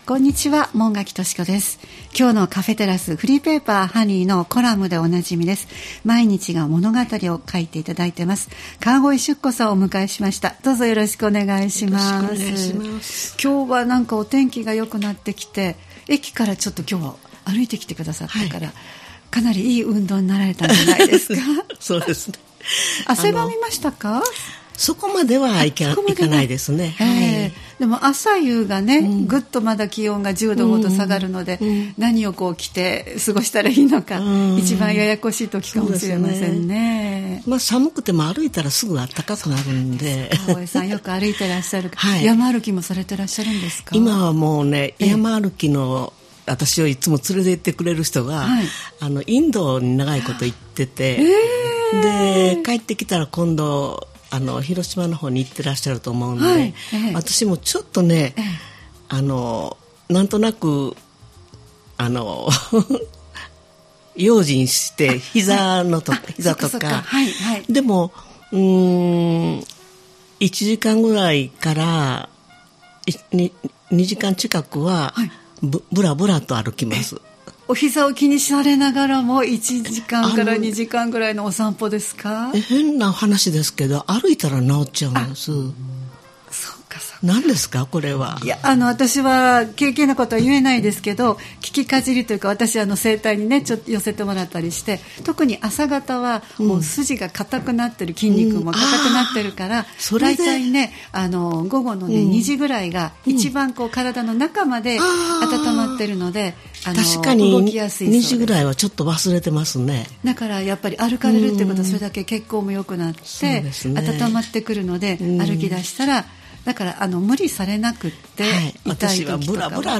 様々なゲストをお迎えするトーク番組「カフェテラス」（再生ボタン▶を押すと放送が始まります）